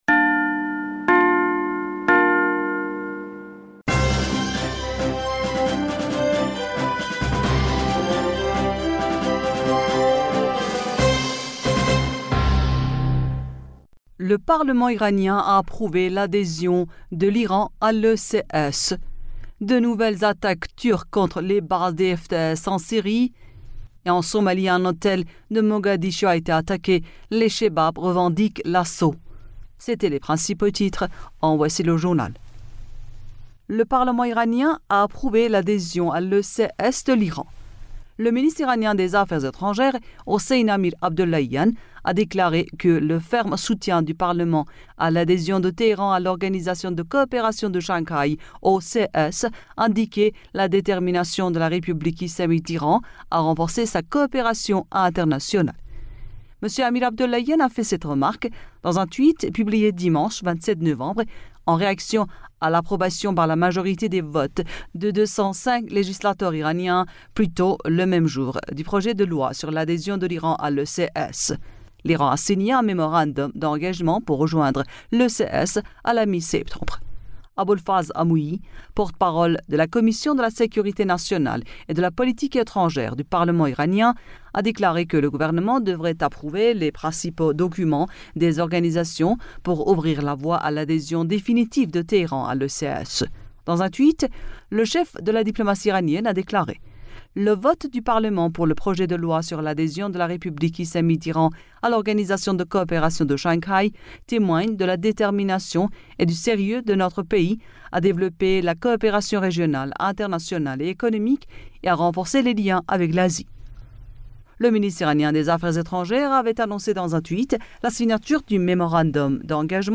Bulletin d'information du 28 Novembre